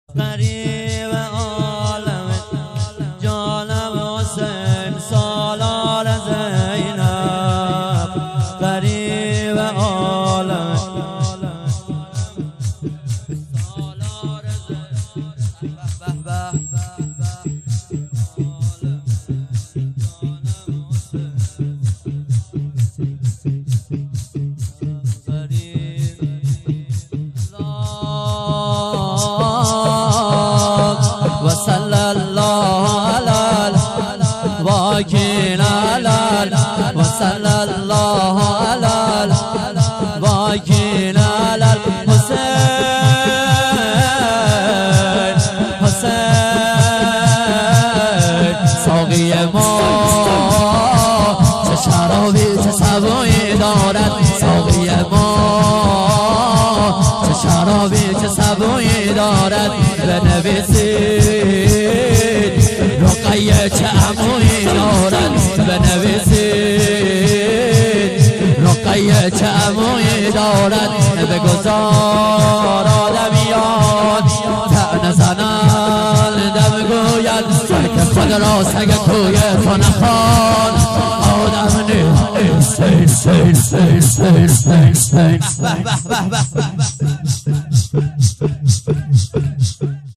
وصل الله علیه (شور)
شور-وصل-الله-علیه.mp3